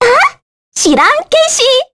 Annette-vox-select_kr.wav